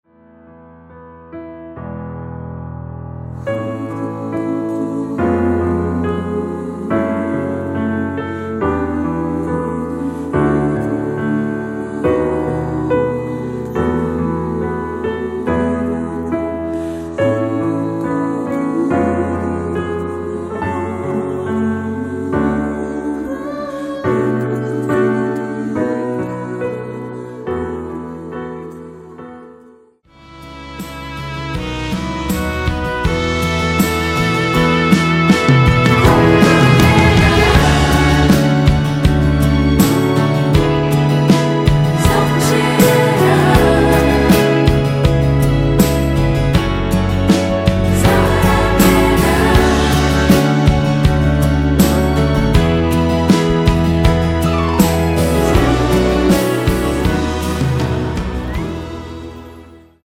노래가 바로 시작하는곡이라 카운트 넣어 놓았으며
박자 맞추기 쉽게 반주 만들어 놓았습니다.
그리고 엔딩이 너무 길고 페이드 아웃이라 라랄라 반복 2번으로 하고 엔딩을 만들었습니다.
원키 코러스 포함된 MR입니다.(미리듣기 확인)
앞부분30초, 뒷부분30초씩 편집해서 올려 드리고 있습니다.